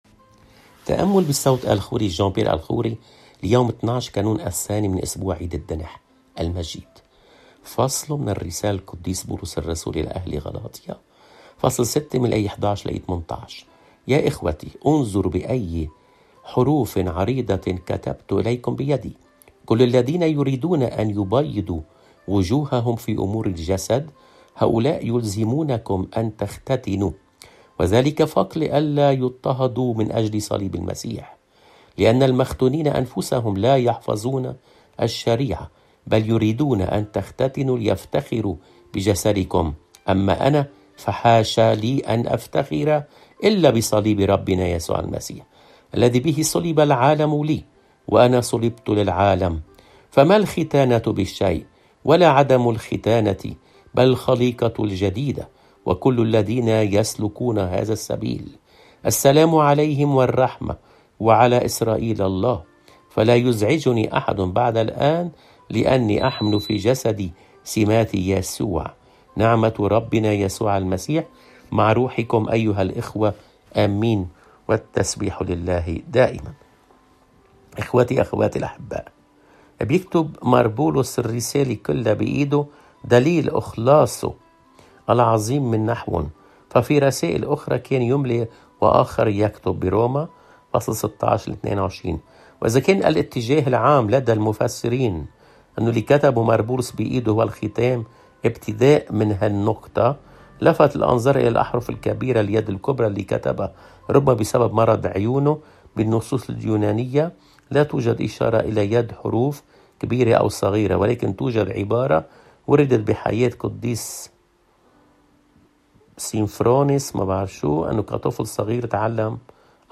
الرسالة